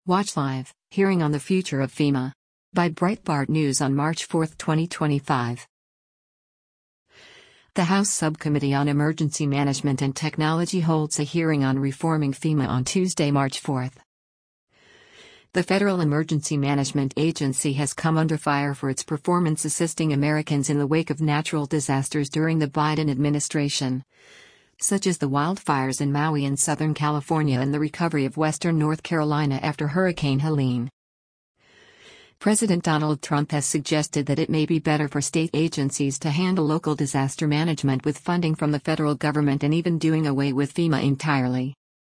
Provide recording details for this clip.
The House Subcommittee on Emergency Management and Technology holds a hearing on reforming FEMA on Tuesday, March 4.